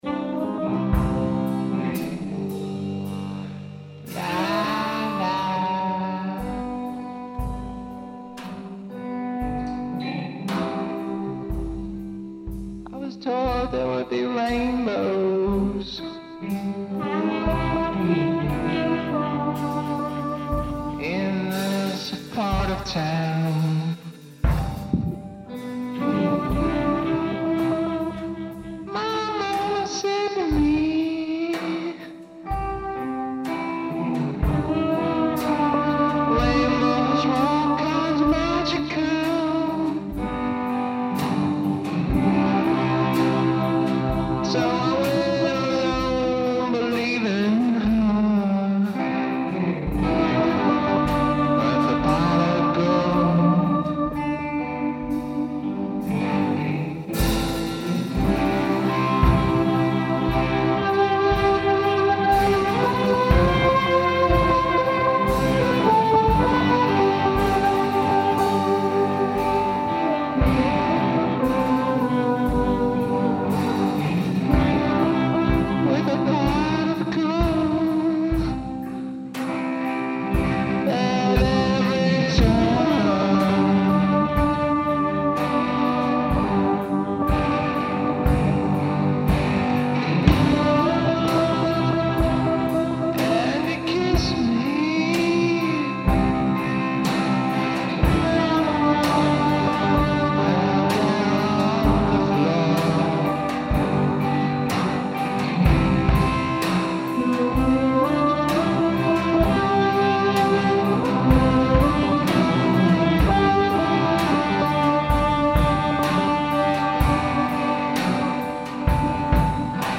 Recorded live at Hot Wood Arts, Red Hook, Brooklyn
Sterero (Pro Tools)